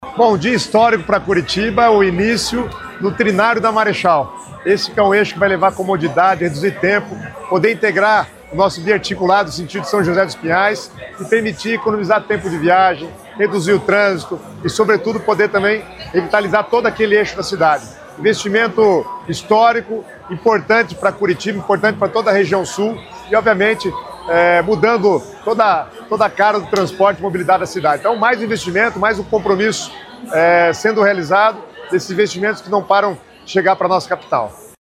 Sonora do secretário das Cidades, Guto Silva, sobre os investimentos na Capital paranaense